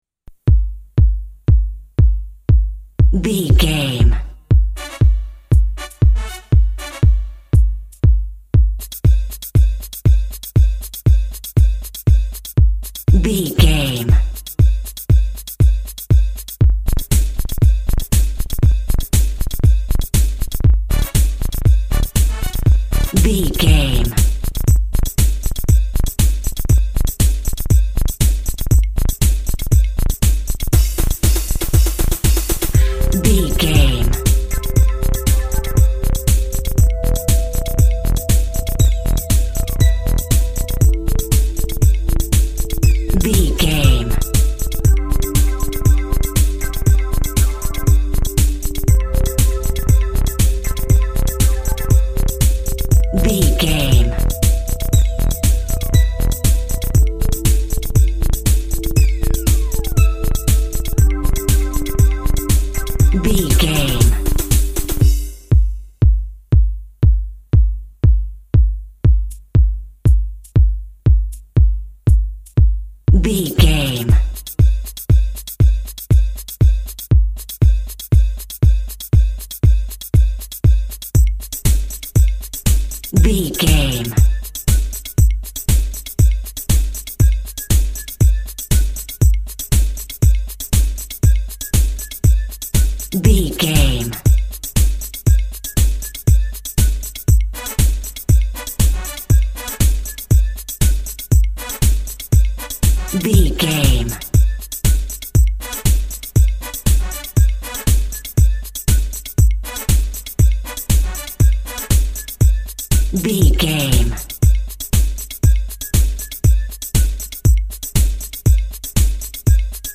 Percussive Electro Music.
Ionian/Major
groovy
futuristic
uplifting
brass
drums
drum machine
techno
electronic music
synth lead
synth bass
Synth Pads